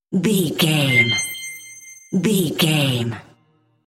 Sound Effects
funny
magical
mystical